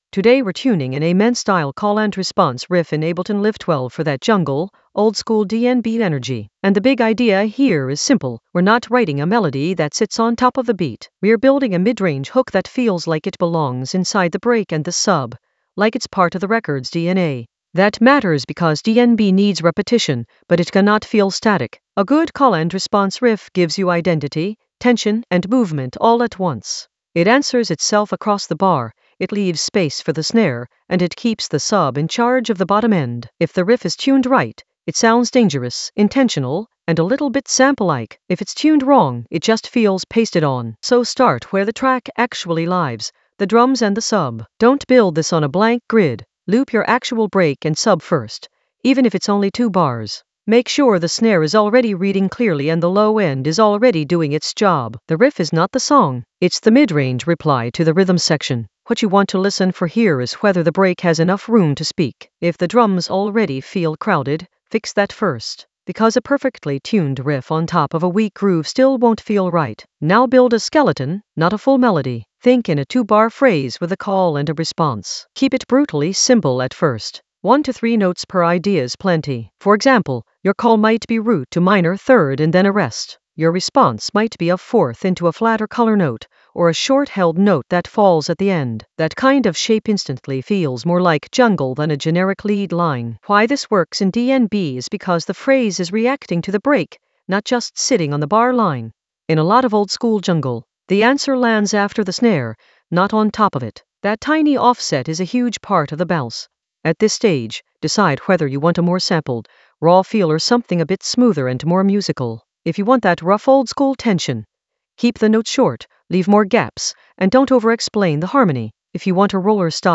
An AI-generated advanced Ableton lesson focused on Tune an Amen-style call-and-response riff in Ableton Live 12 for jungle oldskool DnB vibes in the Workflow area of drum and bass production.
Narrated lesson audio
The voice track includes the tutorial plus extra teacher commentary.